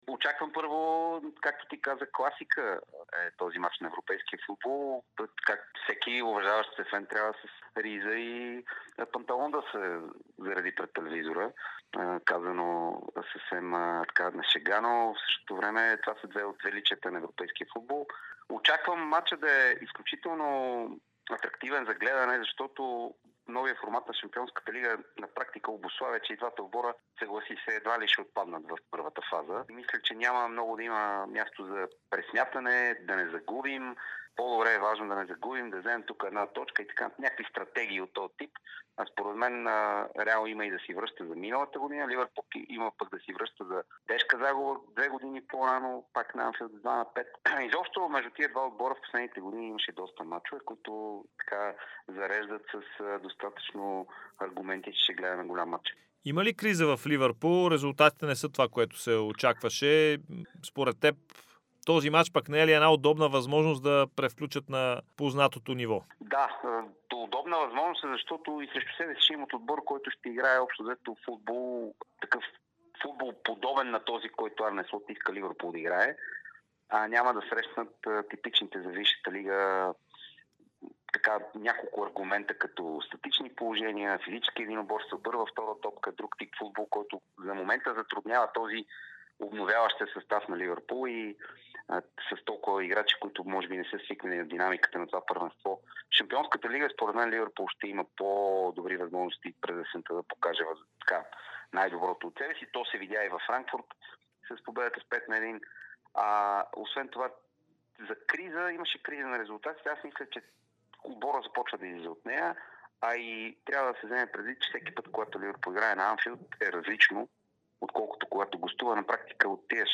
говори за Дарик радио преди футболната класика Ливърпул - Реал Мадрид на „Анфийлд“ от четвъртия кръг в основната фаза на Шампионска лига.